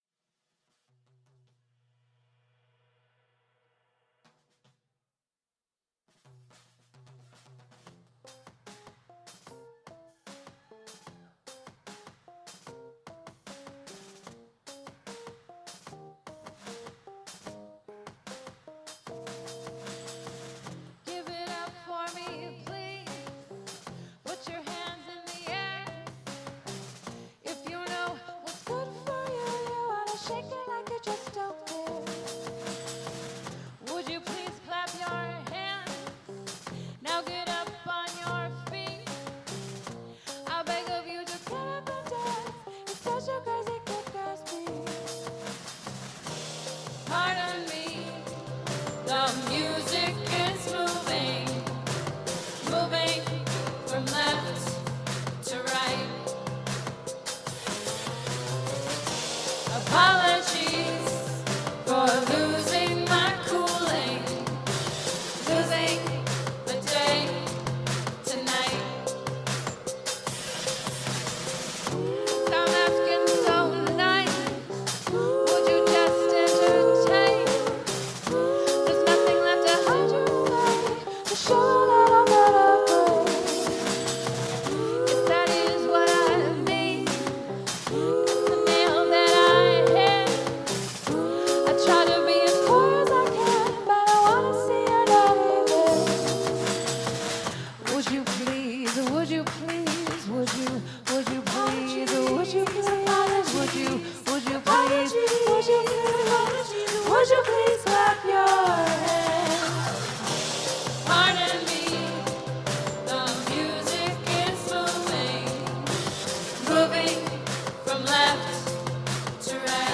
is an American indie pop musical duo from Los Angeles